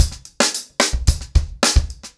TimeToRun-110BPM.11.wav